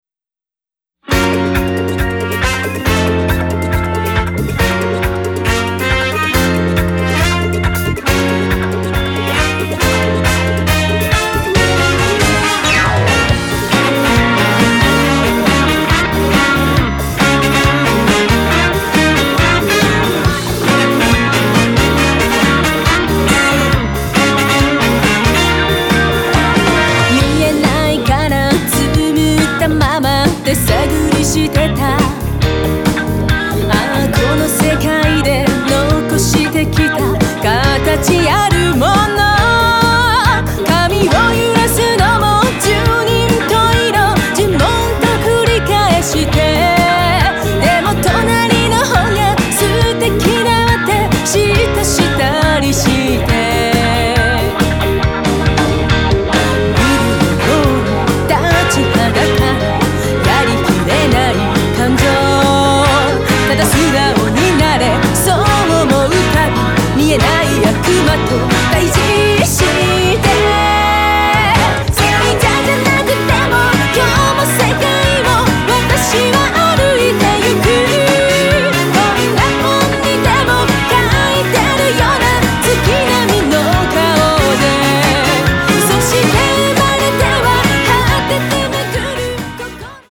クロスフェードデモ
色とりどりのアレンジ満開の東方ヴォーカルポップ＆ロックアレンジ！